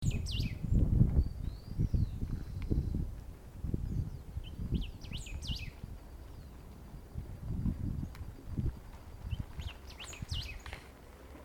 Golden-billed Saltator (Saltator aurantiirostris)
Location or protected area: Reserva Ecológica Costanera Sur (RECS)
Condition: Wild
Certainty: Observed, Recorded vocal